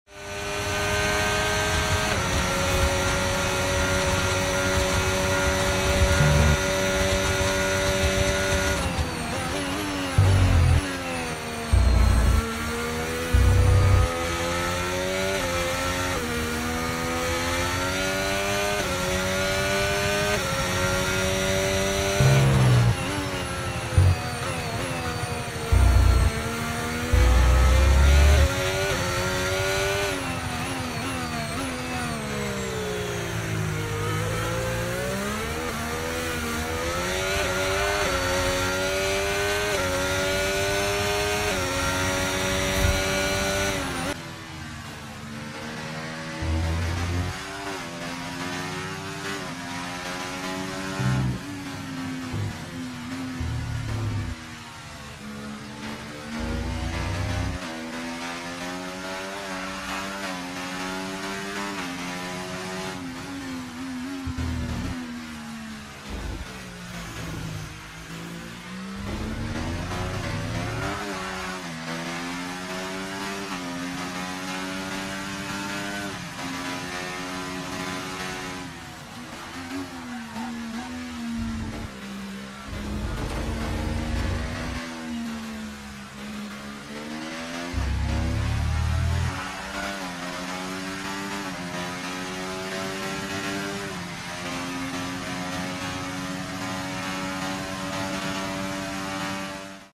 F1 25 VS F1 24 Sound Effects Free Download